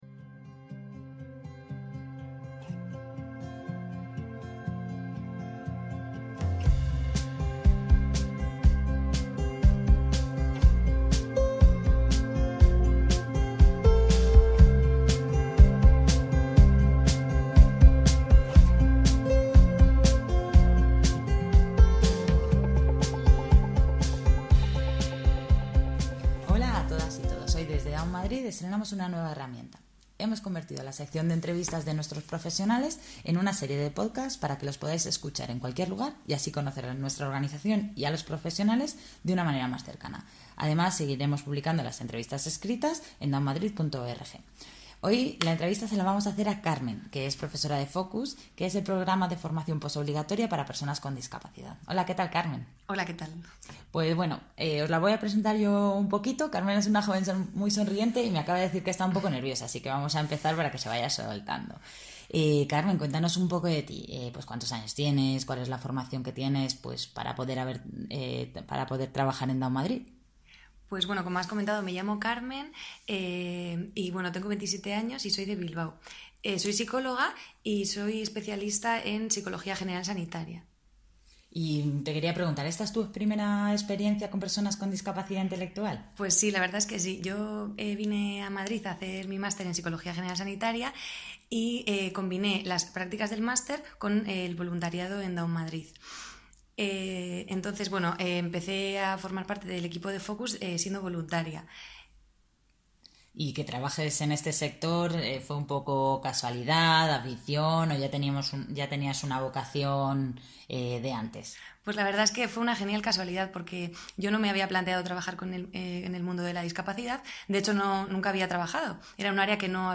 La entrevista de Down Madrid